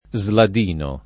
sladino [ @ lad & no ]